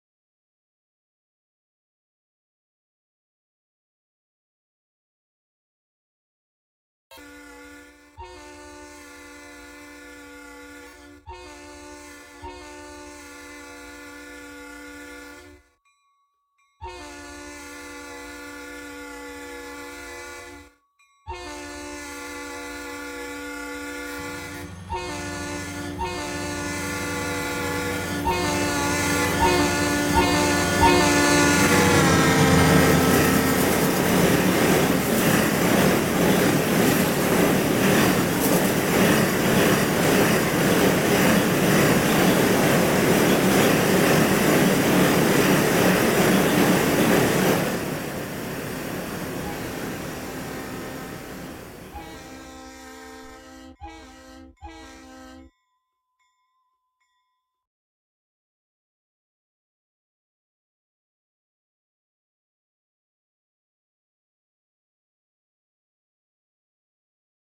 Just some train passing on sound effects free download
Just some train passing on Roblox RO-Scale Sandbox.